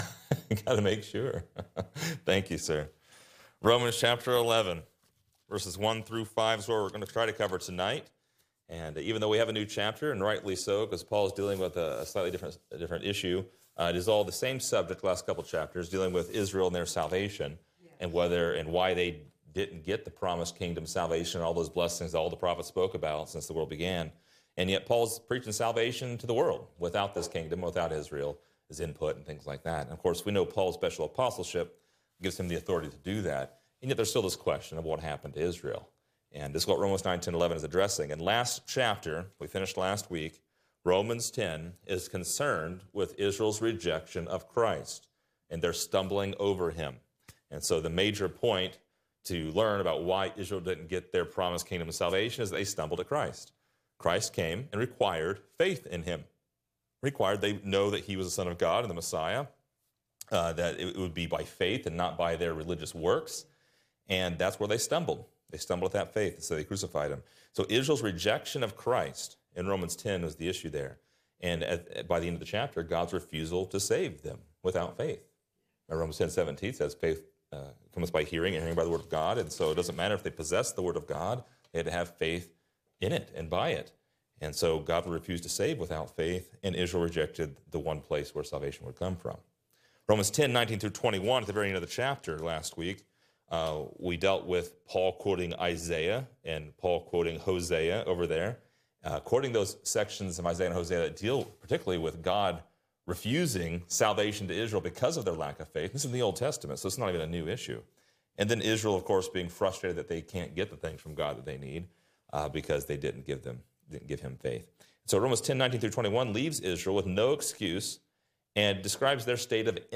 Description: This lesson is part 74 in a verse by verse study through Romans titled: The Remnant of Israel.